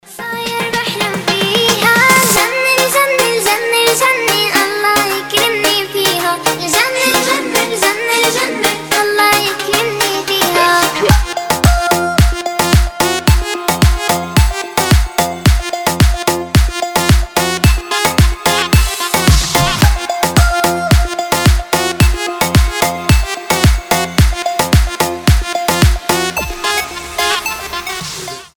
• Качество: 320, Stereo
remix
зажигательные
восточные
детский голос
house
арабские